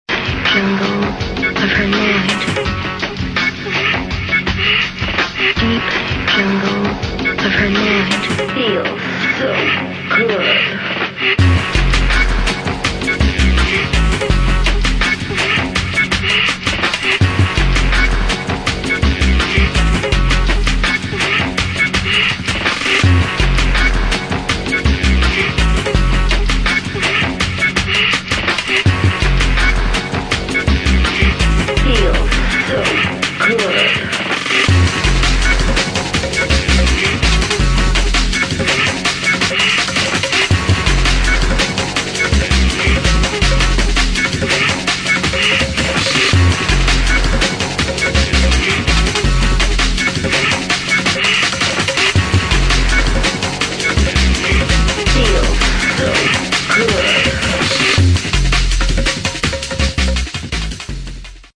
[ DRUM'N'BASS / JUNGLE / OLDSKOOL ]